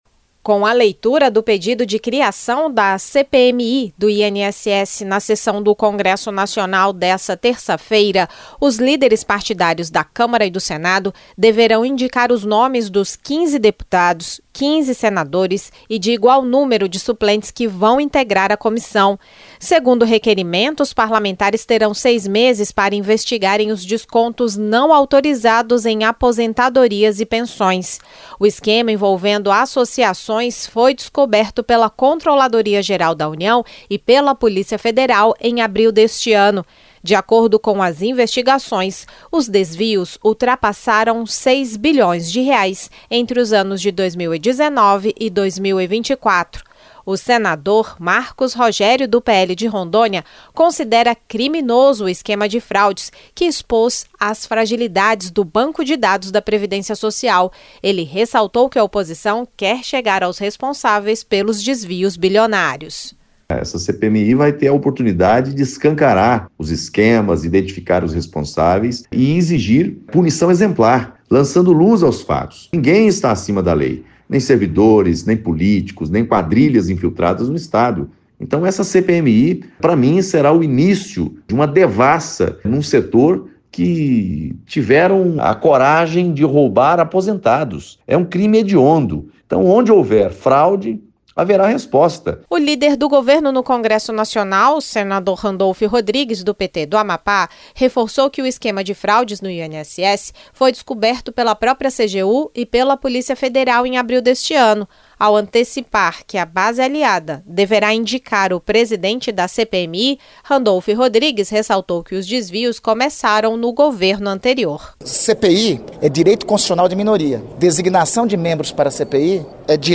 Na sessão do Congresso Nacional desta terça-feira (17), o presidente do Senado, Davi Alcolumbre, leu o requerimento de criação da Comissão Parlamentar Mista de Inquérito do INSS, que vai investigar os descontos não autorizados em aposentadorias e pensões. Ao destacar a iniciativa da oposição, o senador Marcos Rogério (PL-RO) declarou que a CPMI vai atrás dos responsáveis pelos desvios superiores a R$ 6 bilhões, entre 2019 e 2024. O líder do governo no Congresso Nacional, senador Randolfe Rodrigues (PT-AP), confirmou que a base aliada deverá presidir as investigações.